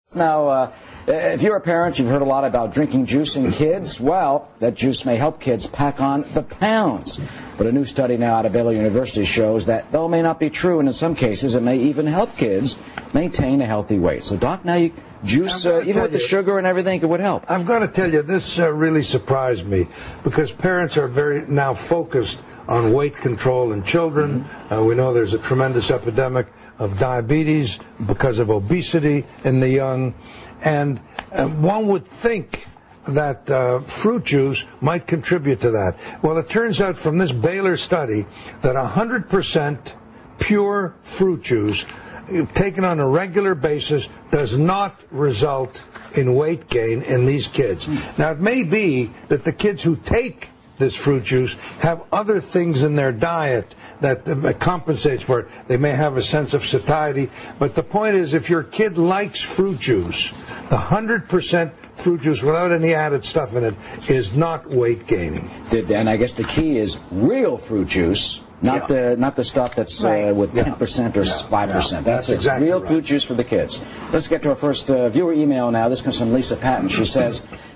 访谈录 Interview 2007-06-05&06-07, 100%果汁是否真健康？